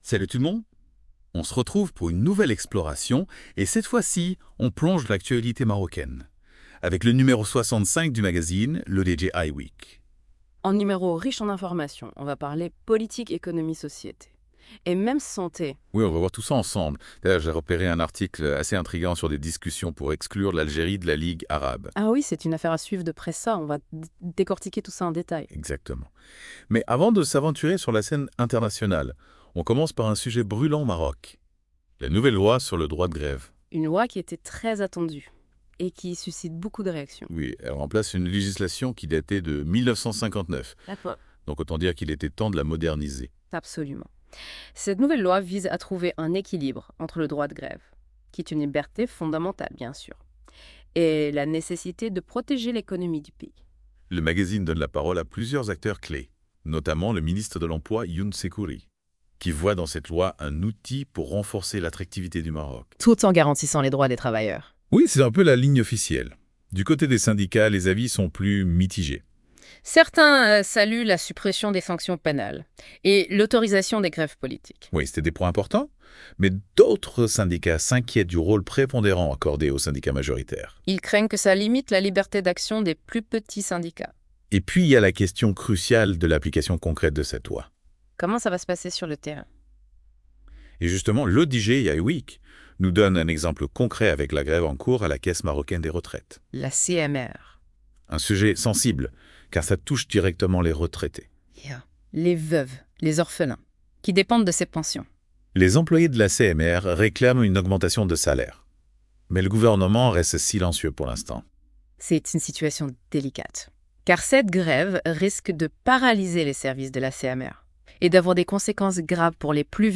Ce podcast - Débat sur le contenu de l’hebdomadaire L’ODJ I-Week N°65 couvre une variété de sujets d'actualité marocaine et internationale, incluant l'adoption d'une nouvelle loi sur le droit de grève, une grève à la Caisse Marocaine des Retraites, la distinction d'un wali marocain, des événements internationaux comme les tensions entre le Hezbollah et Israël et la situation en Syrie, des sujets de santé publique (VIH/Sida, rougeole), et des informations culturelles et sportives (FIFM, musique, etc.). Les chroniqueurs de la Web Radio R212 examinent également des questions sur l'opinion publique marocaine face au changement climatique et la désinformation en ligne, tout en abordant la possible exclusion de l'Algérie de la Ligue Arabe. Enfin, un entretien avec un athlète marocain et des brèves sur les technologies (IA, WhatsApp, etc.) sont inclus.